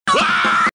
Pancras The Lion Screaming Sfx Sound Effect Download: Instant Soundboard Button